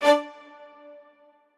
strings3_4.ogg